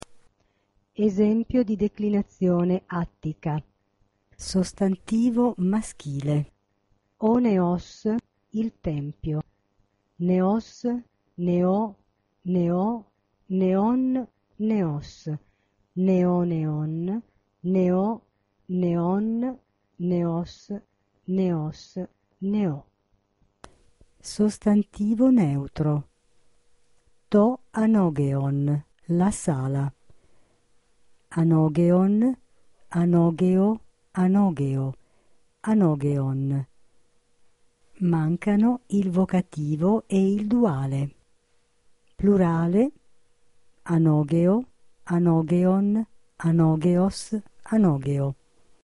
seconda_decl_attica) permette di sentire la lettura dei nomi maschili e neutri della seconda declinazione attica.